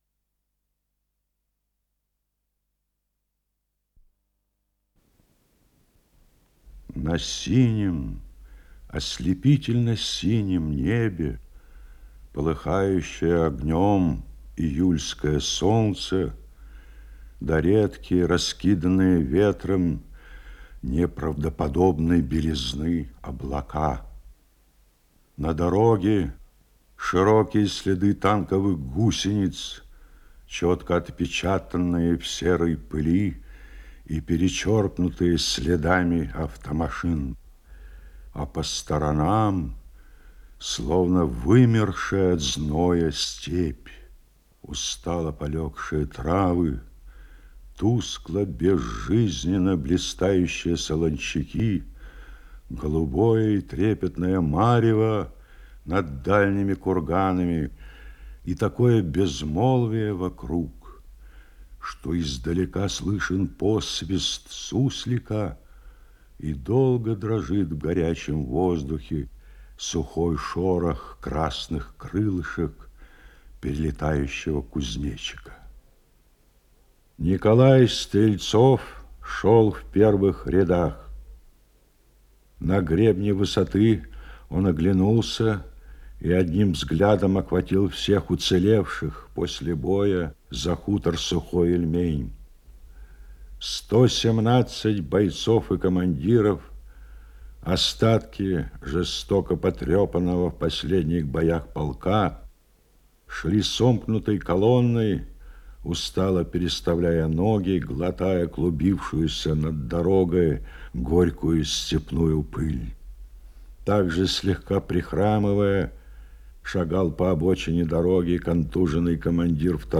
Исполнитель: Сергей Бондарчук - чтение